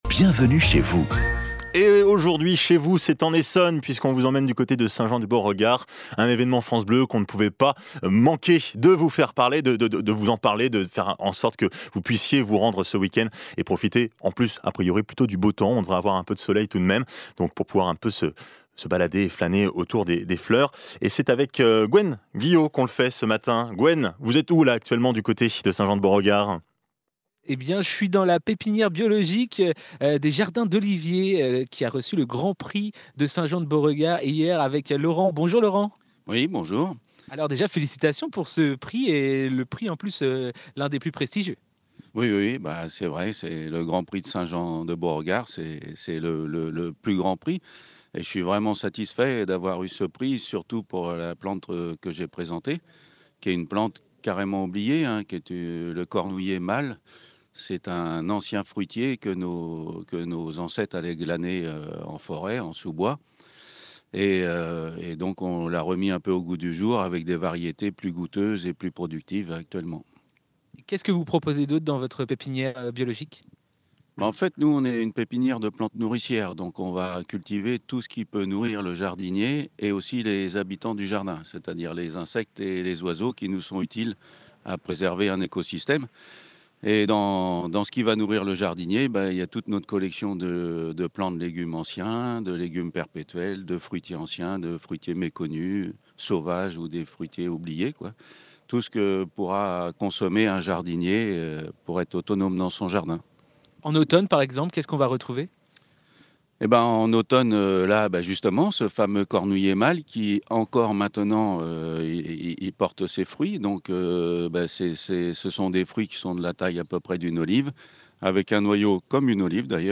Reportage de France Bleu sur la pépinière Les jardins d’Ollivier à Saint Jean de Beauregard
Retrouvez ci-dessous le reportage de France Bleu sur notre pépinière à l’occasion du Grand Prix de Saint Jean de Beauregard.